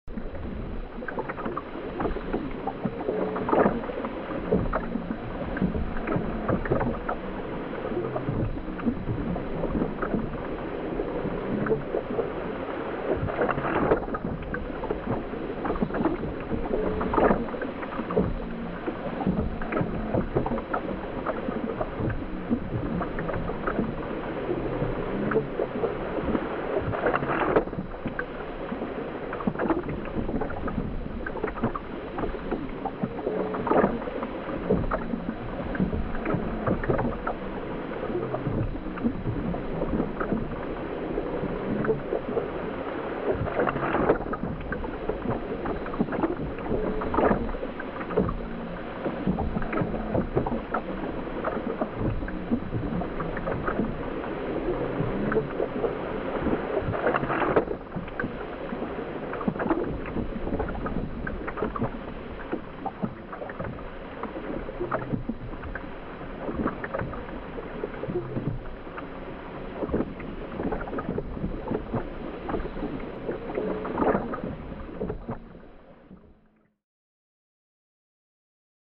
جلوه های صوتی
دانلود صدای وال 23 از ساعد نیوز با لینک مستقیم و کیفیت بالا
برچسب: دانلود آهنگ های افکت صوتی انسان و موجودات زنده دانلود آلبوم صدای حیوانات آبی از افکت صوتی انسان و موجودات زنده